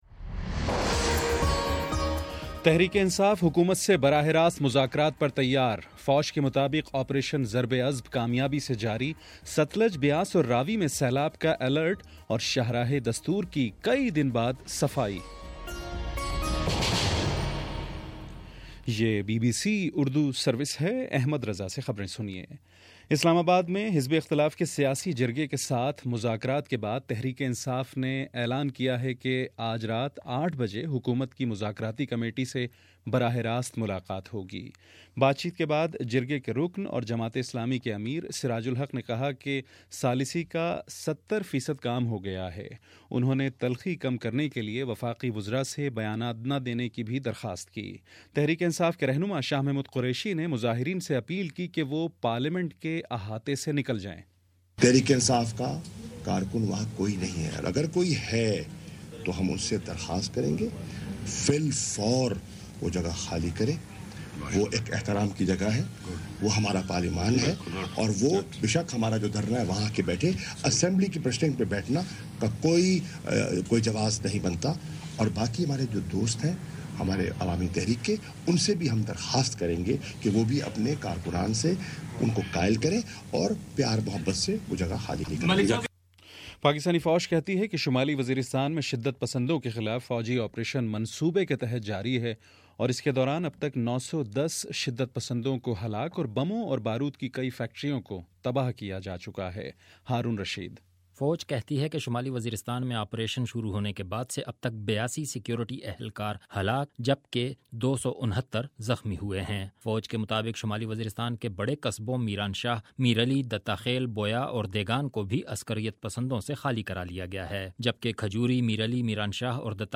تین ستمبر شام سات بجے کا نیوز بُلیٹن